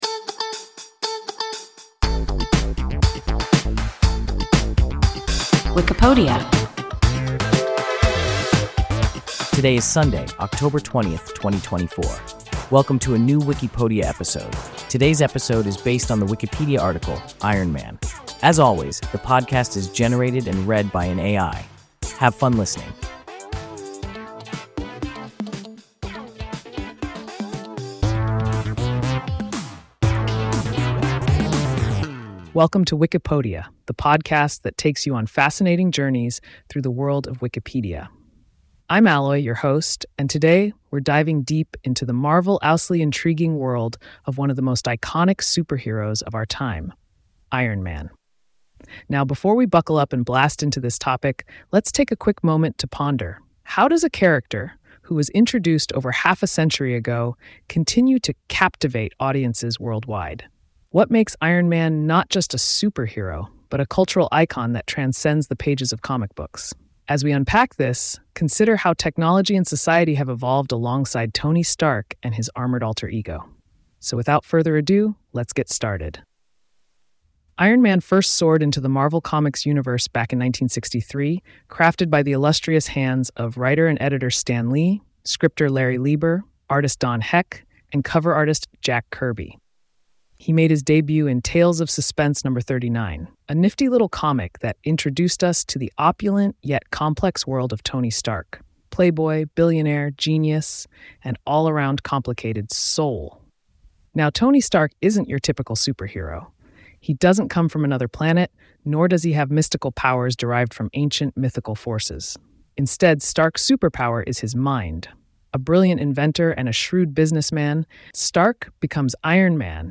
Iron Man – WIKIPODIA – ein KI Podcast